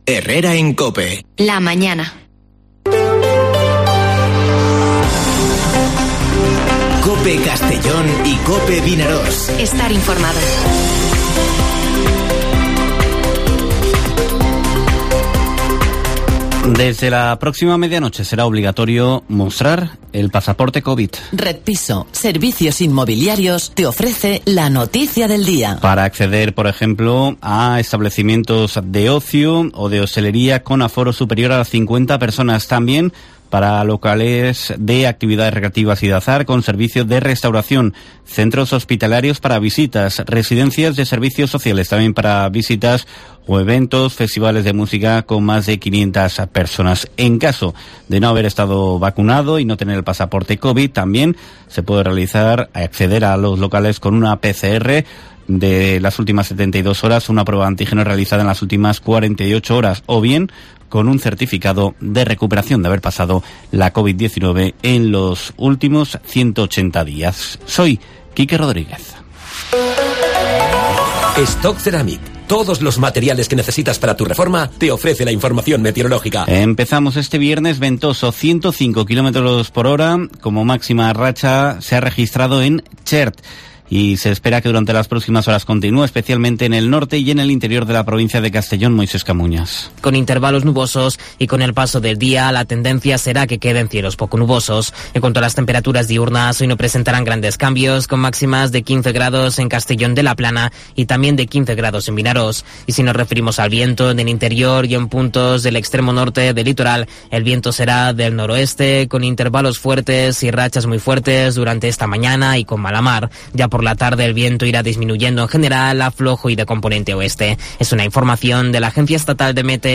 Informativo Herrera en COPE en la provincia de Castellón (03/12/2021)